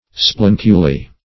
Splenculi - definition of Splenculi - synonyms, pronunciation, spelling from Free Dictionary
splenculi.mp3